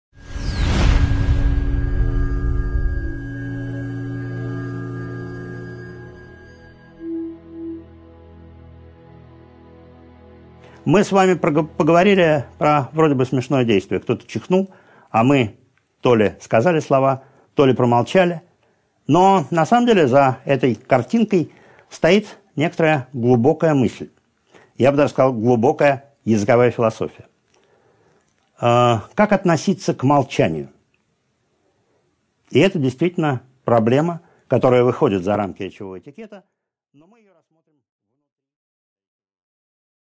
Аудиокнига 2.5 Молчание | Библиотека аудиокниг